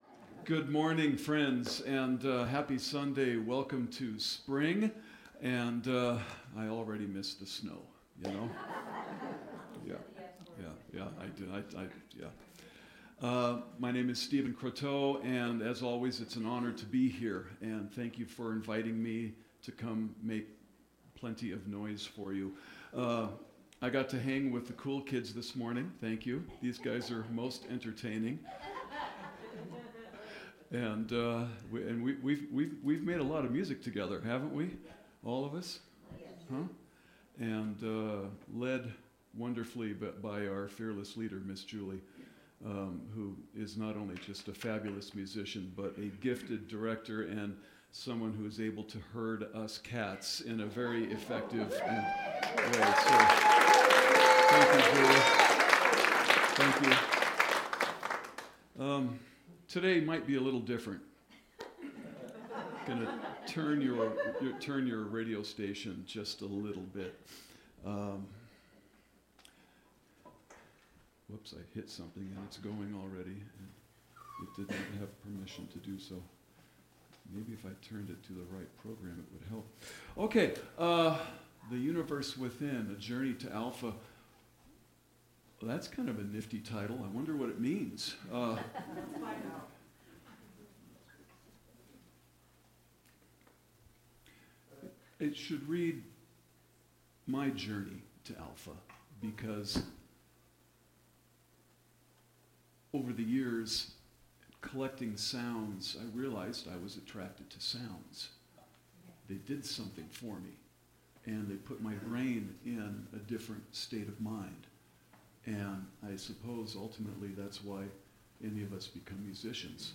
The audio recording (below the video clip) is an abbreviation of the service. In this particular case, it features a series of unique instrumentation that spans the Message, Meditation, and Featured Song sequences of our typical service program.
drums
He plans to present a special ambient and atmospheric sound immersion using synthesizers and various percussion instruments.
vocals
piano